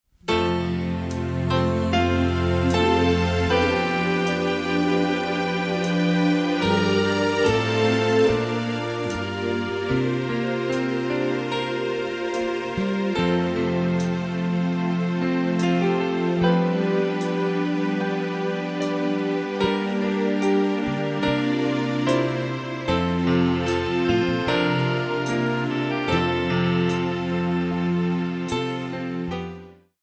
Bb/B
MPEG 1 Layer 3 (Stereo)
Backing track Karaoke
Pop, 1970s